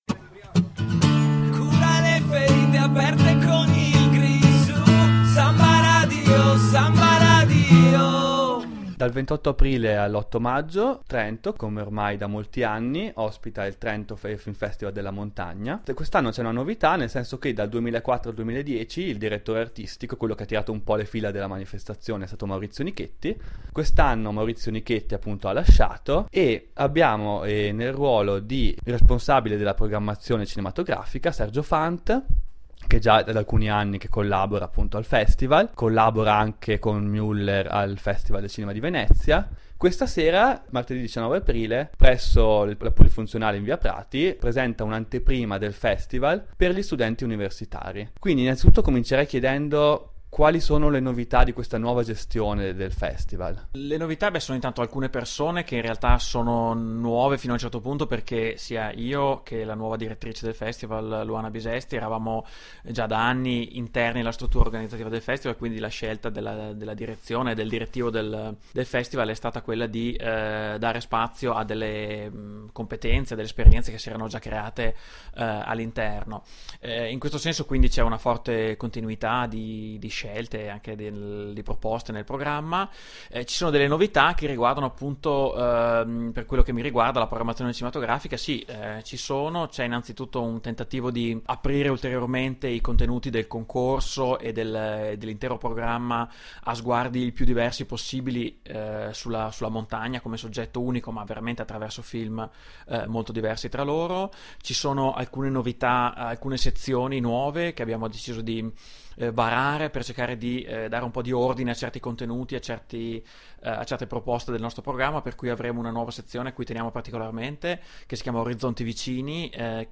Trento Film Festival, intervista